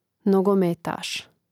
nogomètāš nogometaš